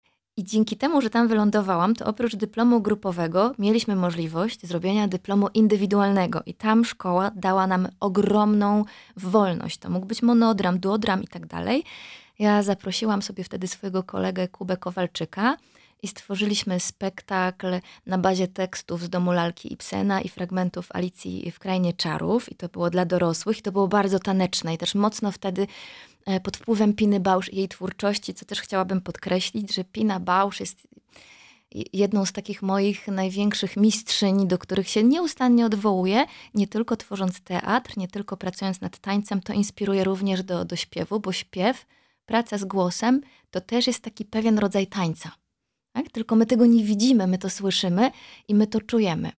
W rozmowie w studiu Radio Rodzina aktorka opowiada o genezie projektu muzycznego, sile teatru oraz autorskiej metodzie pracy z ciałem i emocjami.